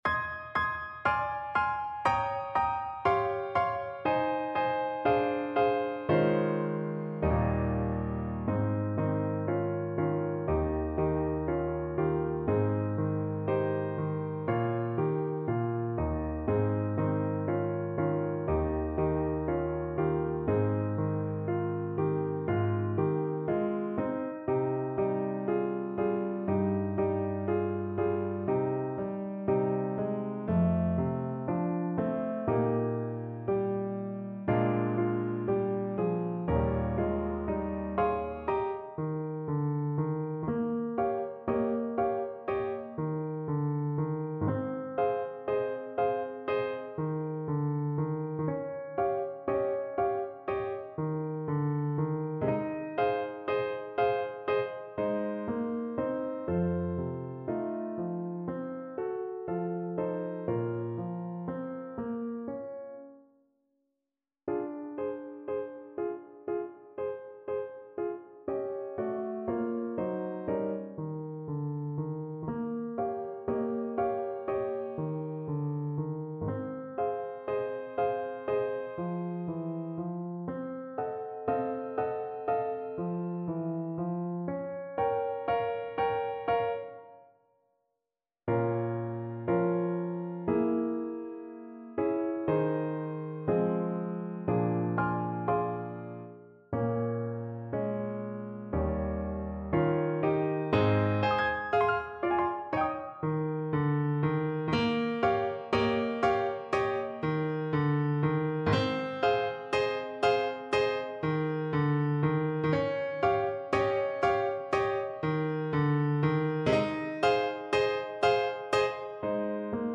Alto Saxophone
4/4 (View more 4/4 Music)
~ = 120 Moderato
Pop (View more Pop Saxophone Music)